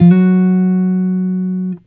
Guitar Slid Octave 07-F#2.wav